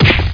rockhit1.mp3